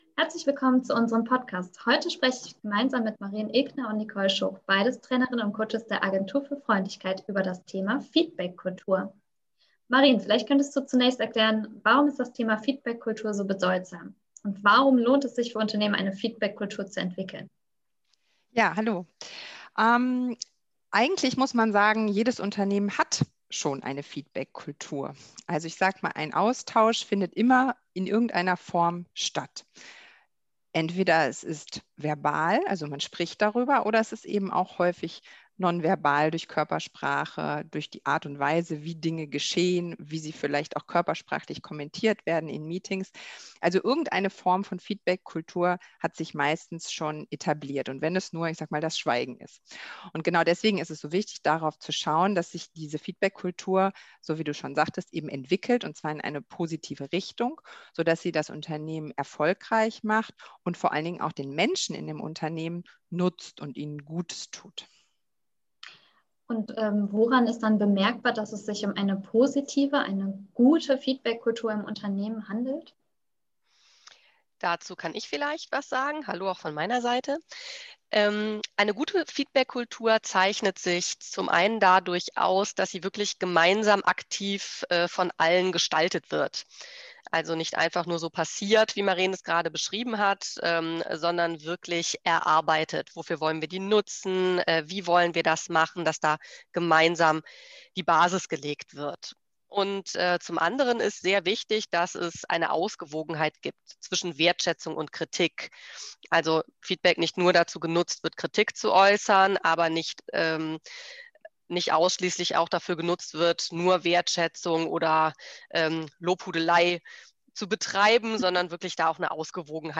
Feedbackkultur in Unternehmen: Ein Interview aus der Praxis
Alternativ zum Textbeitrag finden Sie das Interview hier als Tonspur.
tonspur-interview-feedbackkultur.mp3